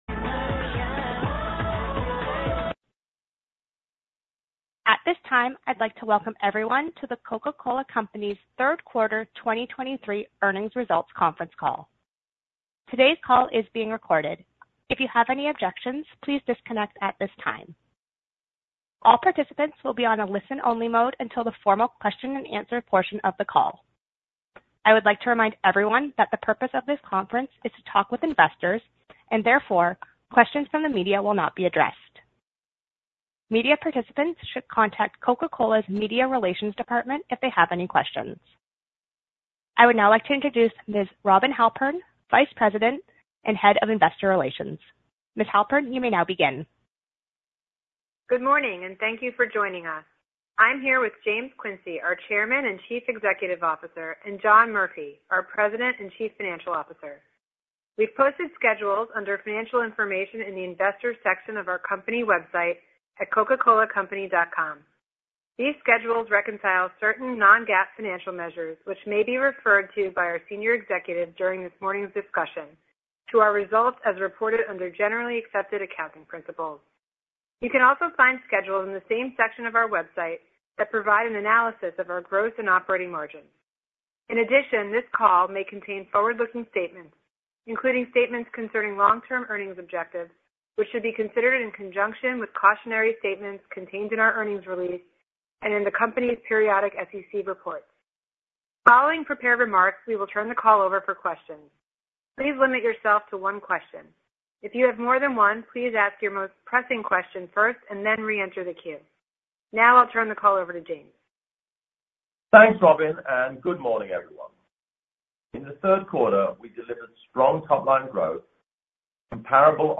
Earnings Call Q3 2023 Audio